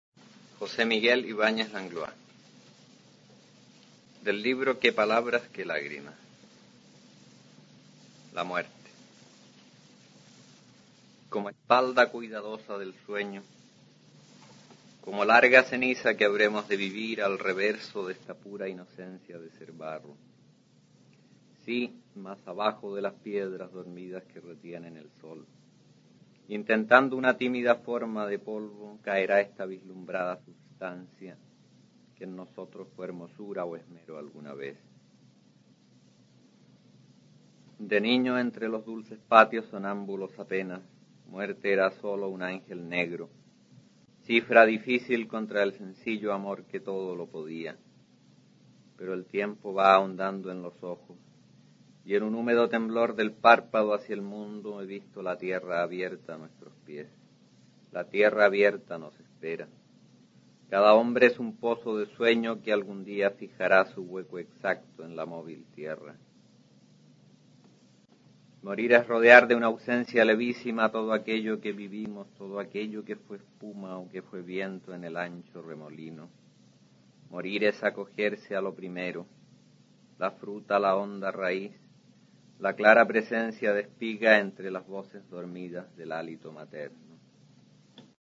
Aquí se puede oír al autor chileno José Miguel Ibáñez Langlois recitando su poema La muerte, del libro "Qué palabras, qué lágrimas" (1954).
Poesía